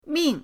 ming4.mp3